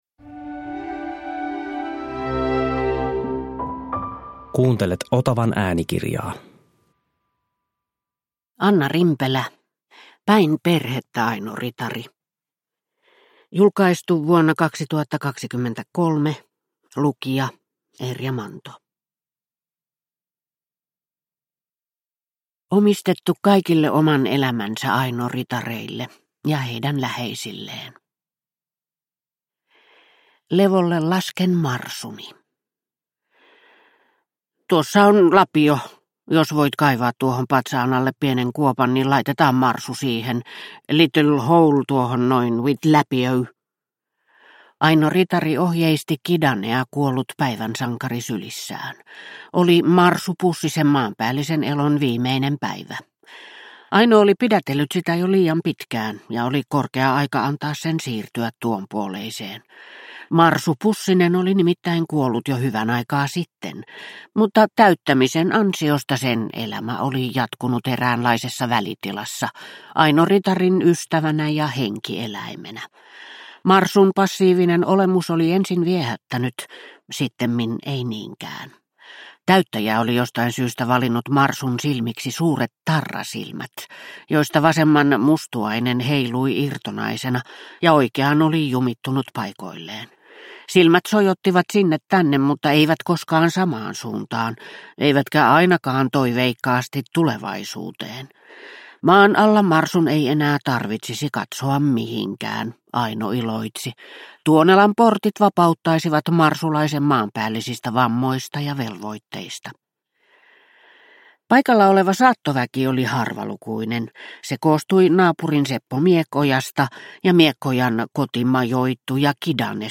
Päin perhettä, Aino Ritari – Ljudbok